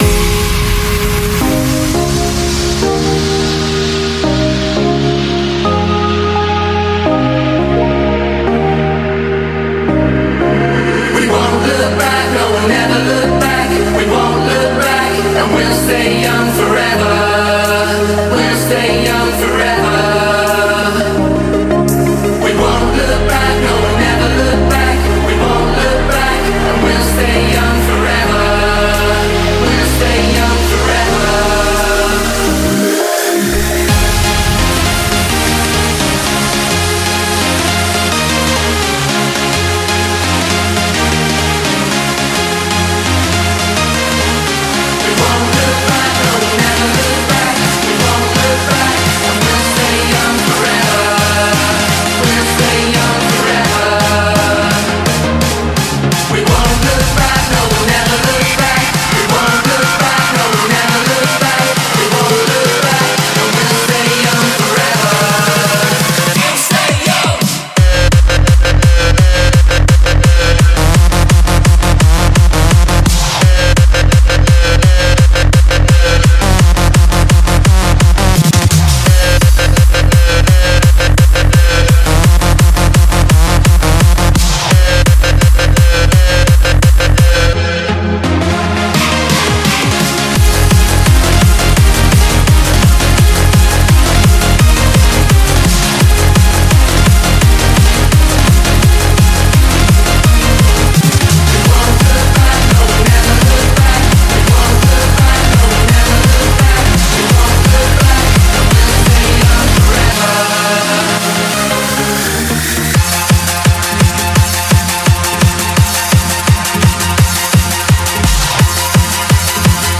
BPM170
Audio QualityMusic Cut